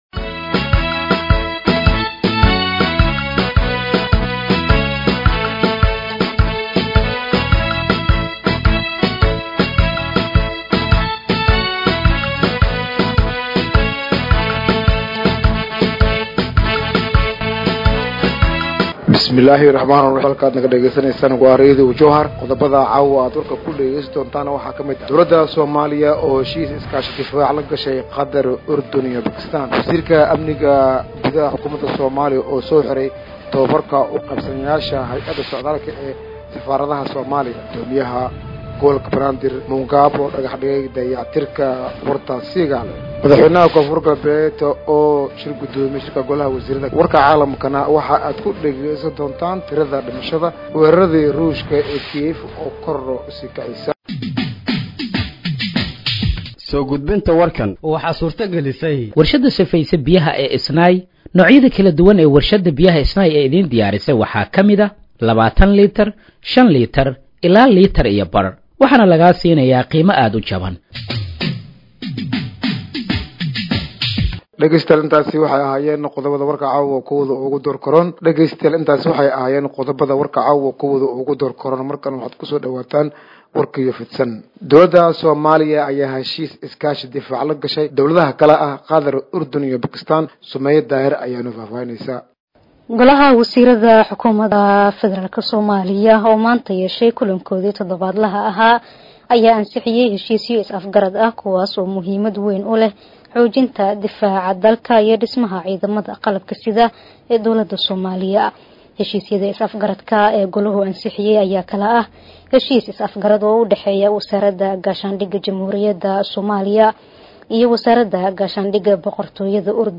Dhageeyso Warka Habeenimo ee Radiojowhar 28/08/2025